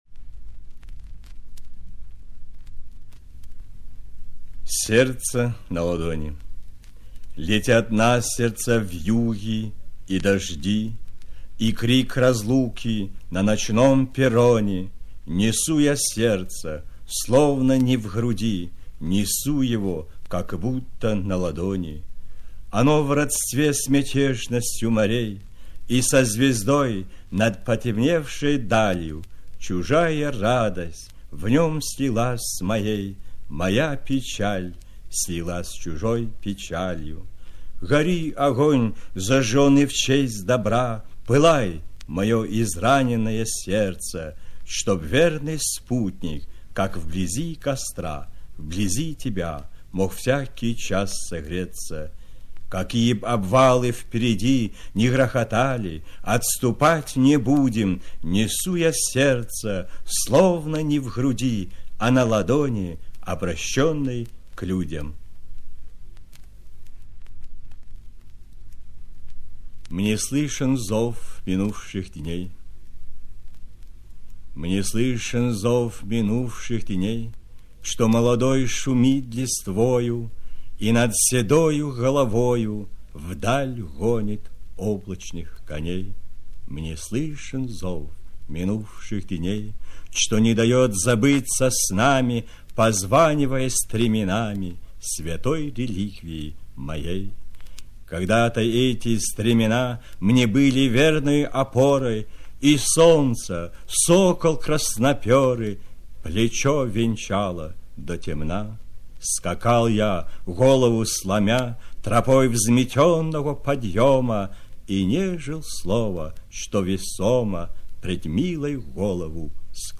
Кешоков Алим - Сборник стихов (читает Алим Кешоков)